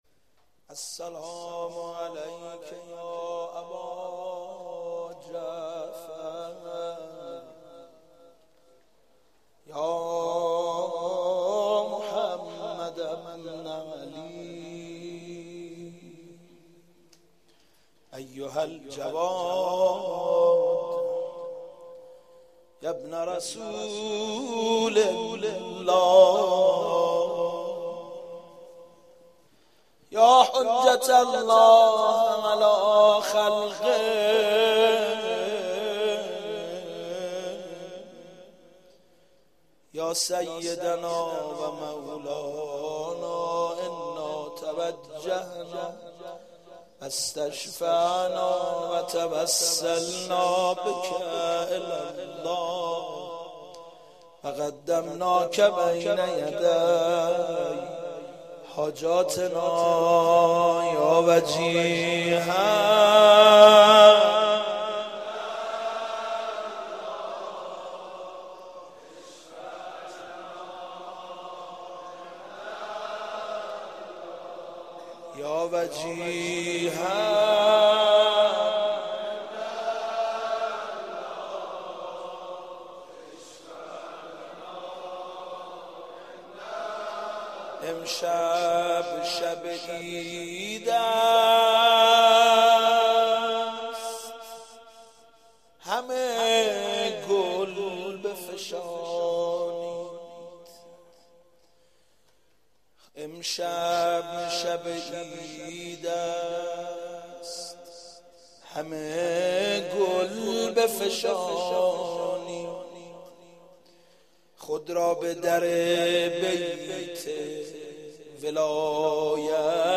ولادت حضرت امام جواد علیه السلام 1391
امشب شب عید است همه گل بفشانید | مدح